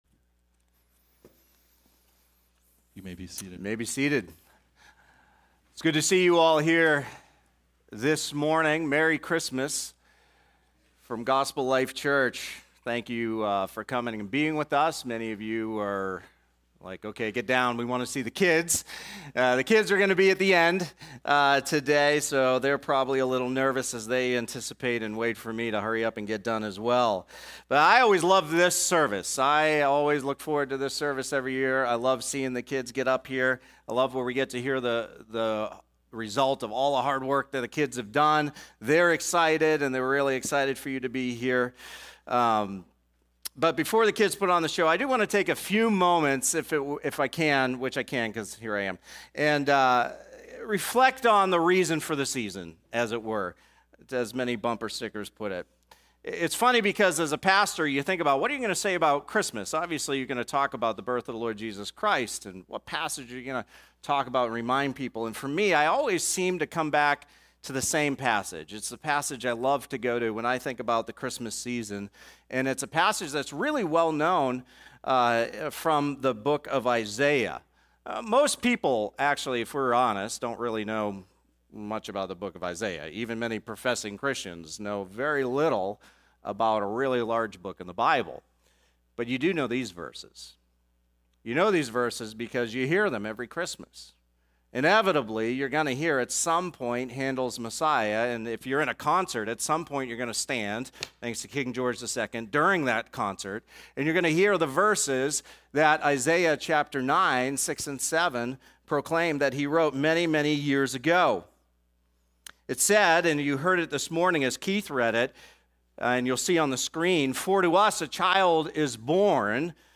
Christmas Sermon